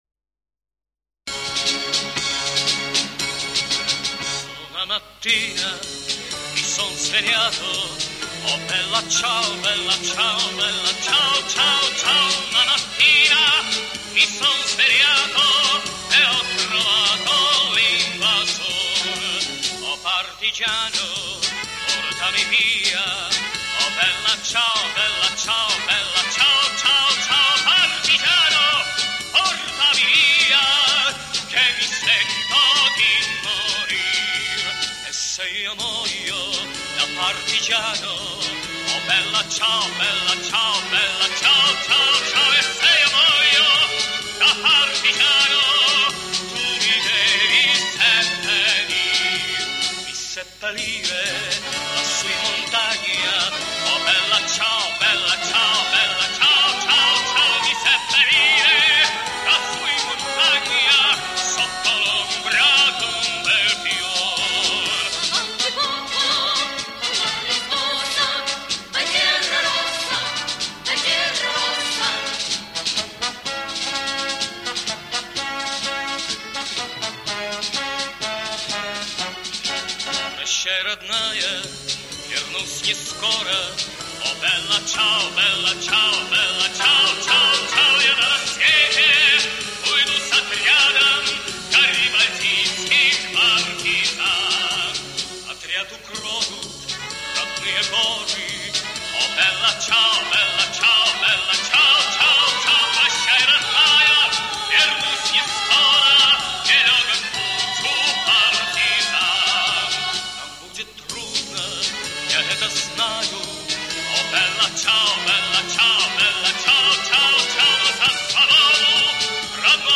И несколько известных песен в исполнении Дина Рида.